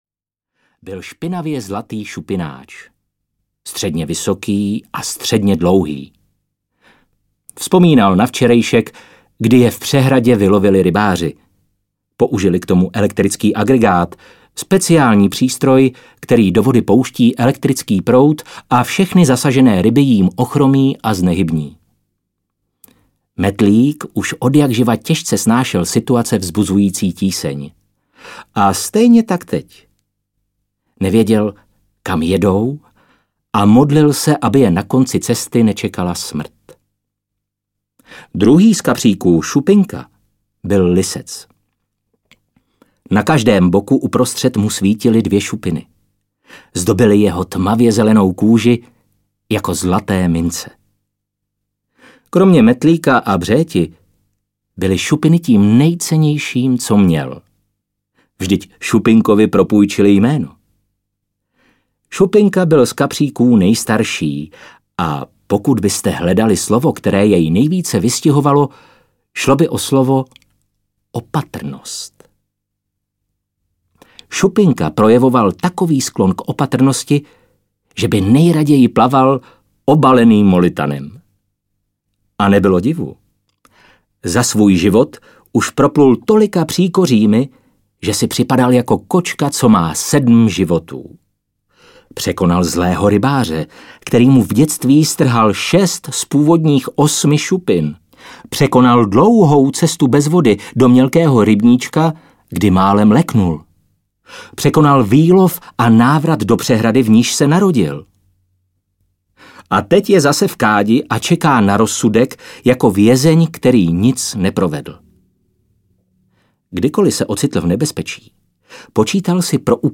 Ukázka z knihy
kaprik-metlik-v-rece-audiokniha